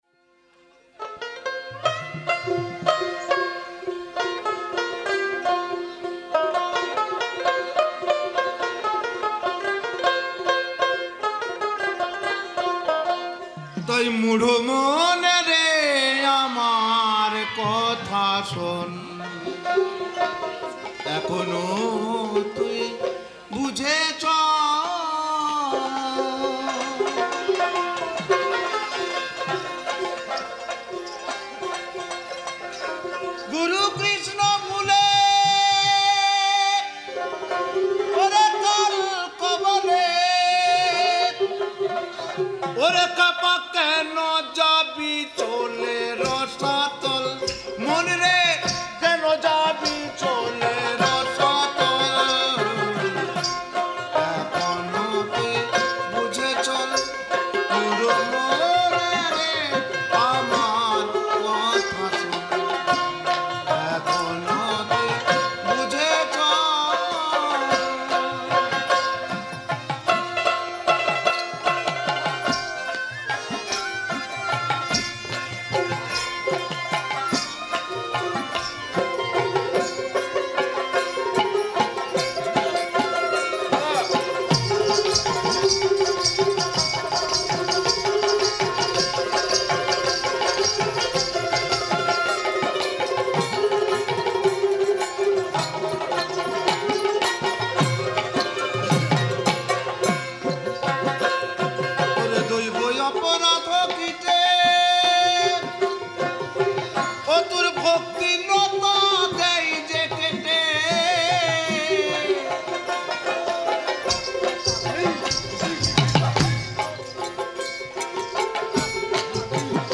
He was a robust man with a strong voice and a performer with a delicate hand on the dotara.
He always stood out with his trademark dotara playing and energetic singing.